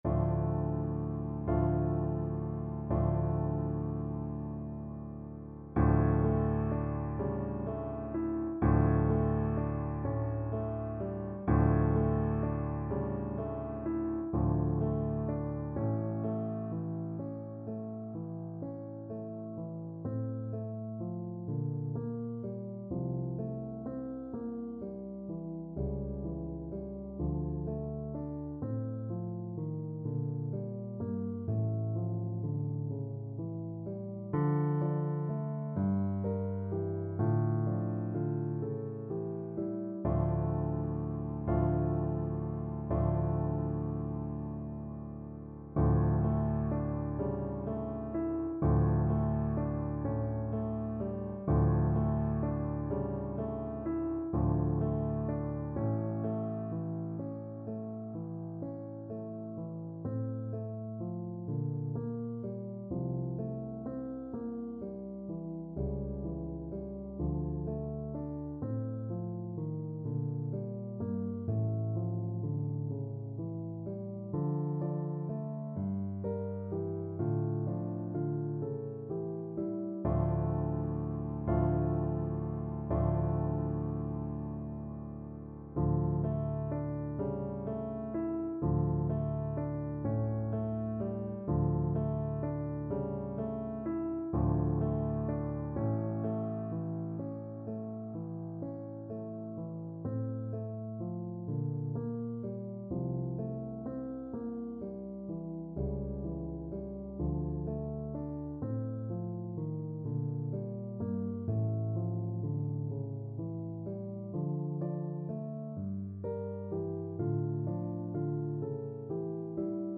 Play (or use space bar on your keyboard) Pause Music Playalong - Piano Accompaniment Playalong Band Accompaniment not yet available transpose reset tempo print settings full screen
6/8 (View more 6/8 Music)
F major (Sounding Pitch) (View more F major Music for Voice )
. = 42 Andante con moto (View more music marked Andante con moto)
Classical (View more Classical Voice Music)